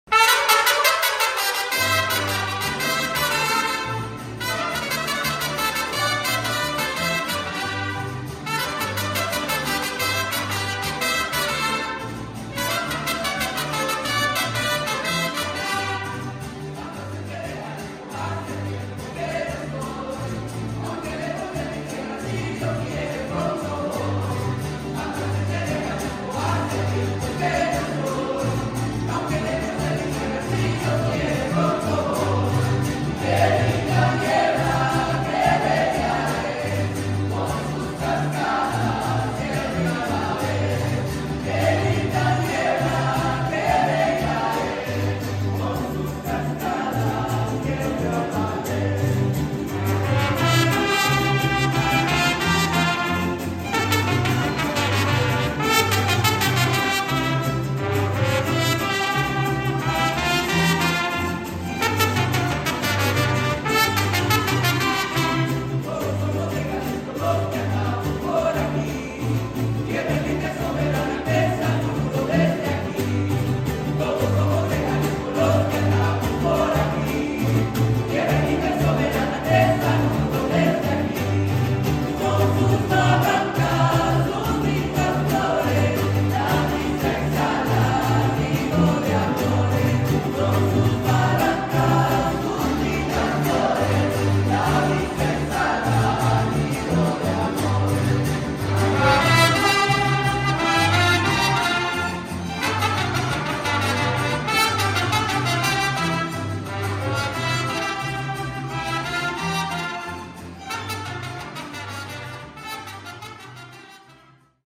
Mariachi_Leones_del_Monte-WSU_Mariachi_Herencia_Concert(excerpt).mp3